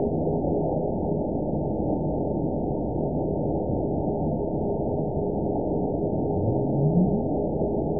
event 922721 date 03/22/25 time 15:01:05 GMT (2 months, 3 weeks ago) score 9.61 location TSS-AB04 detected by nrw target species NRW annotations +NRW Spectrogram: Frequency (kHz) vs. Time (s) audio not available .wav